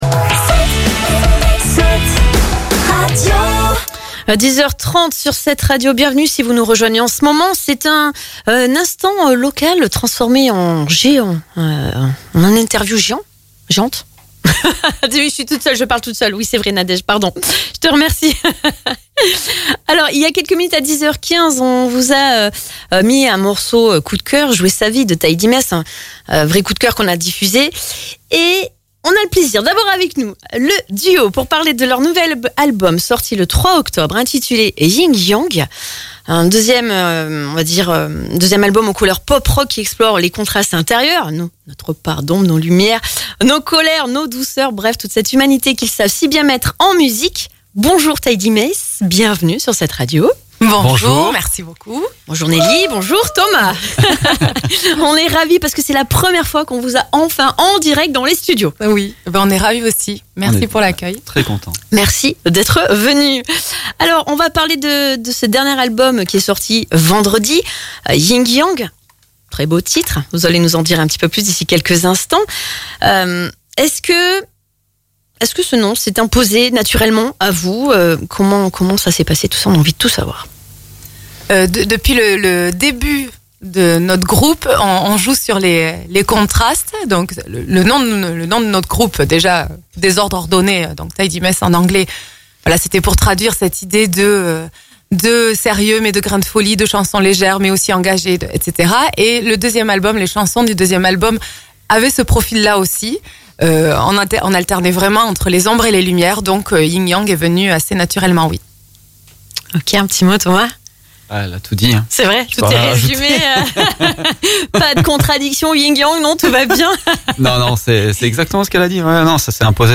Pour (re)vivre cette belle rencontre musicale, écoutez l’interview complète ci-dessous
Une complicité évidente, une énergie communicative et une émotion vraie… TidyMess nous a embarqués dans leur univers pop-rock à la fois sensible et puissant